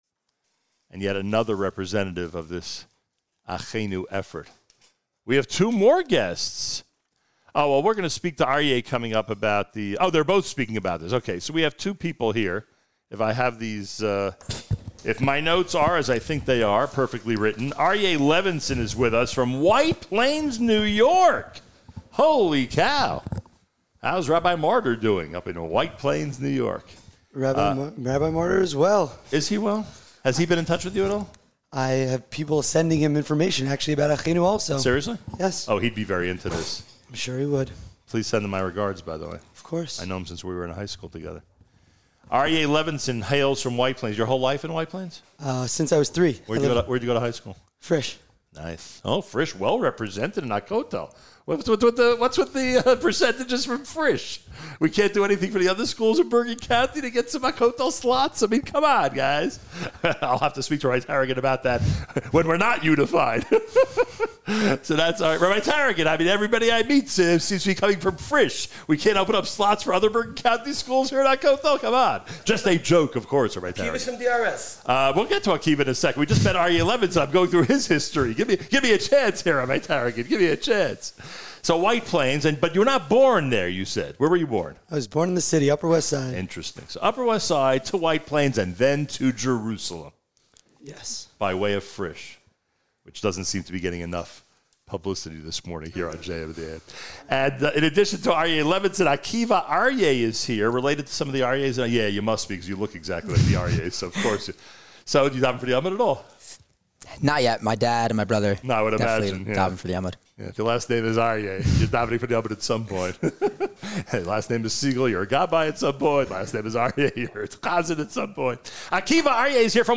By :  | Comments : Comments Off on The “Not Going Anywhere” Campaign Providing Support for Gap-Year Students in Israel | Category : Interviews, Israel, News